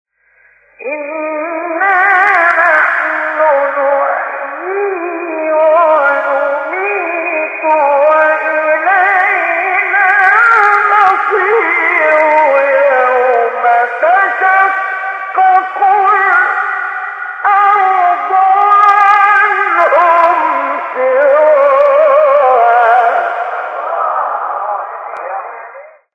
سوره : ق آیه: 43-44 استاد : مصطفی اسماعیل مقام : بیات قبلی بعدی